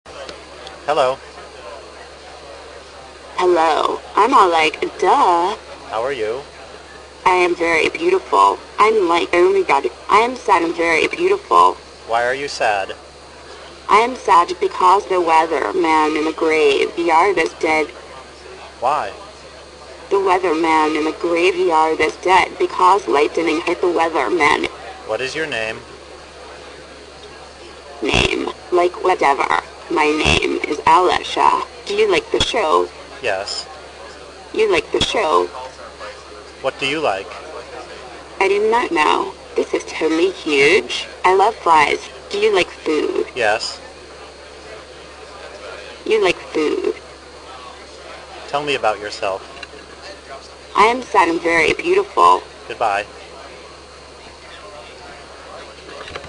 One of the things Brainhat can do is hold a conversation.
This audio clip was taken at SpeechTek '02, in late October. It demonstrates Brainhat following a mixed initiative conversation, asking questions and giving answers.
The recognition was done with Dragon NaturallySpeaking, Version 6. The valley girl voice was provided compliments of Rhetorical Systems.